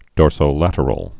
(dôrsō-lătər-əl)